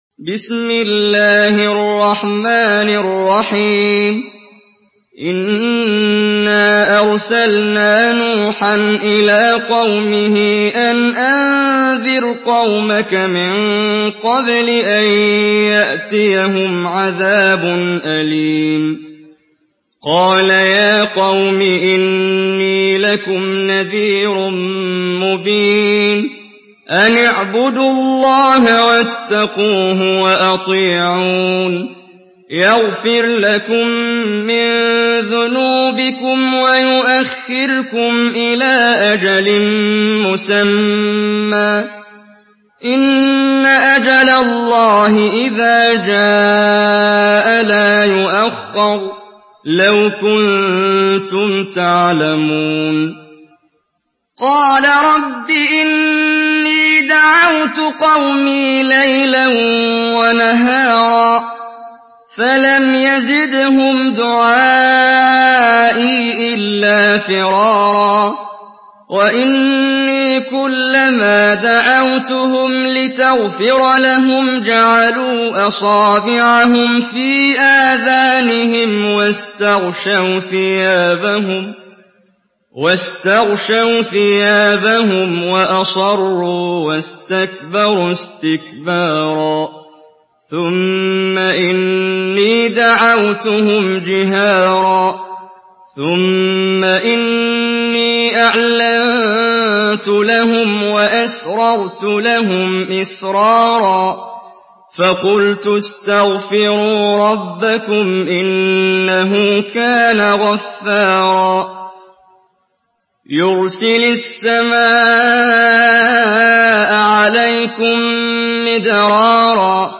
Surah Nuh MP3 by Muhammad Jibreel in Hafs An Asim narration.
Murattal Hafs An Asim